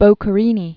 Boc·che·ri·ni
(bōkə-rēnē, bŏkə-, bōkkĕ-), Luigi 1743-1805.